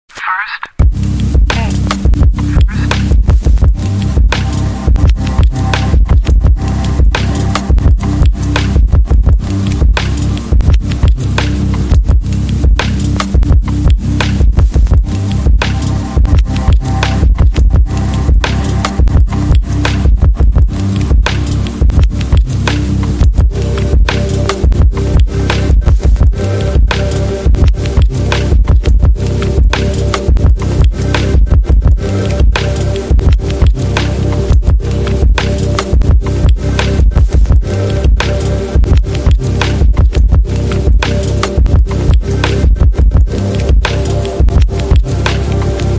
You Just Search Sound Effects And Download. tiktok laughing sound effects Download Sound Effect Home